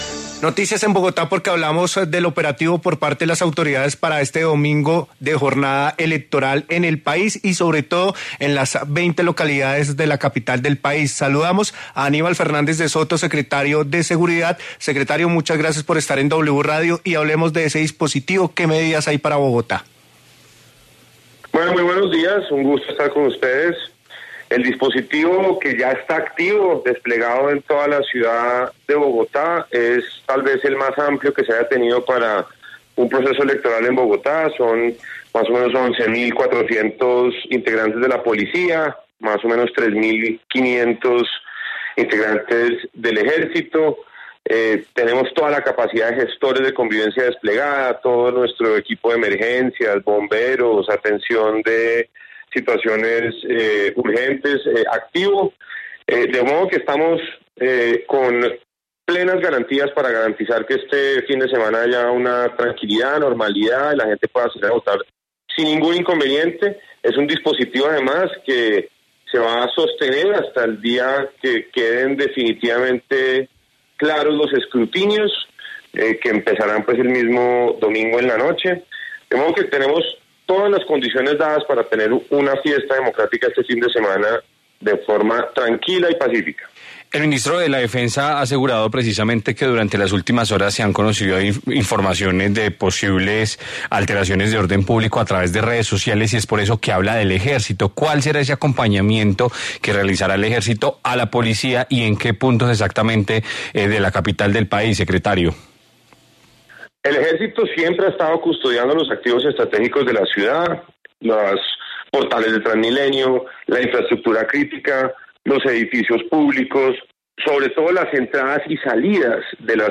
En diálogo con La W, Aníbal Fernández, secretario de Seguridad de Bogotá, se refirió a las medidas adoptadas para garantizar el normal desarrollo de las elecciones del domingo 19 de junio.